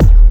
Shady_Kick_3.wav